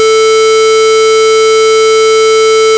square_long_a4.aiff